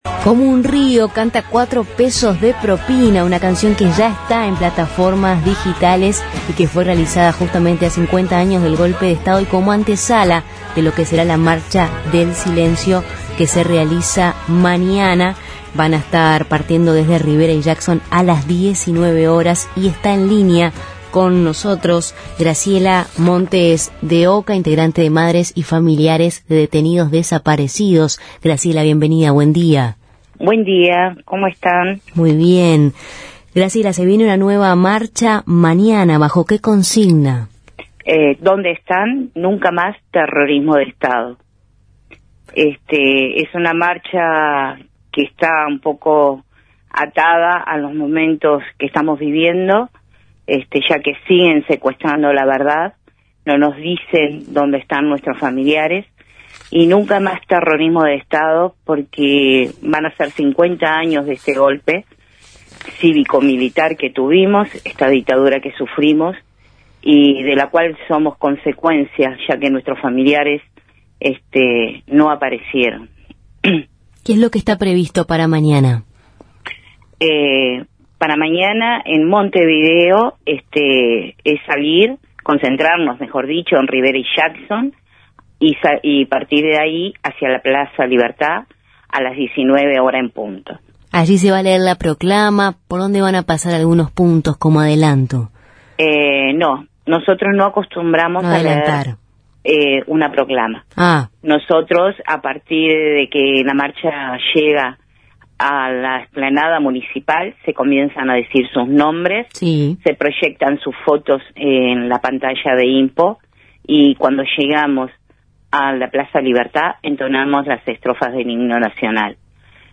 entrevistamos